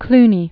(klnē, kl-nē, klü-)